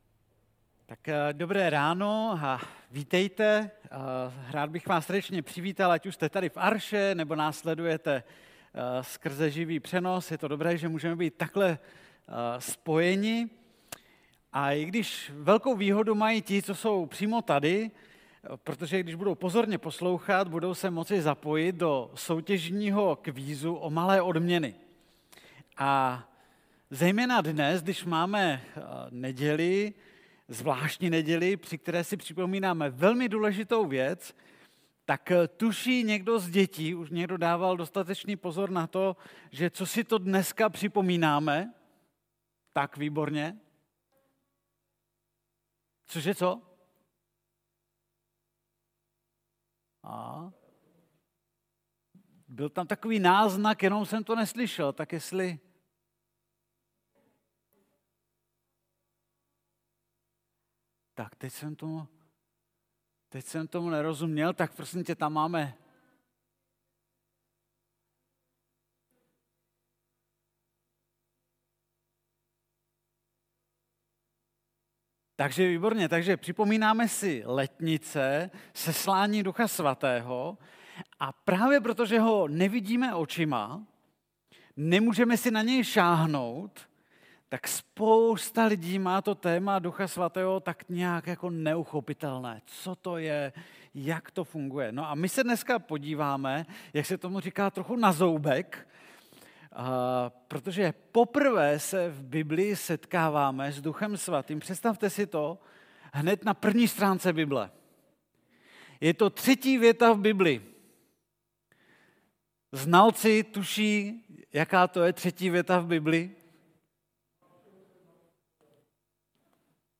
Bohoslužba pro rodiny s dětmi (ale nejen pro ně)
Kategorie: Nedělní bohoslužby